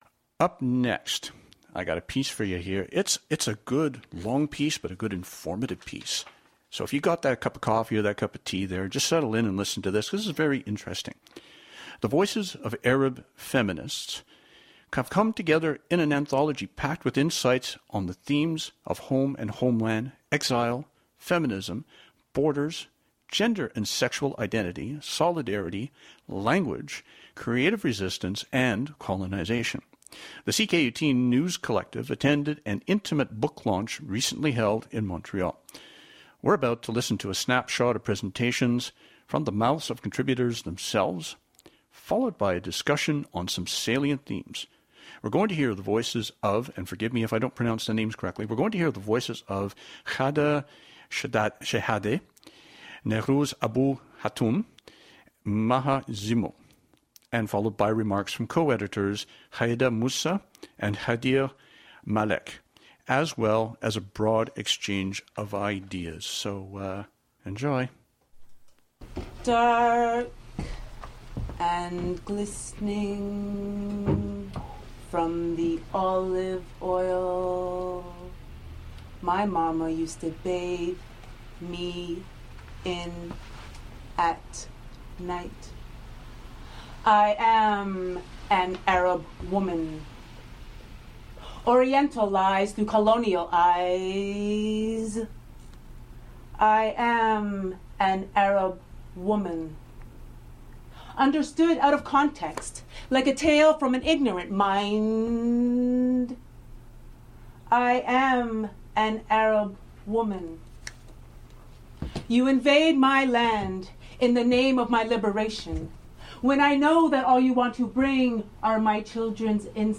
Book launch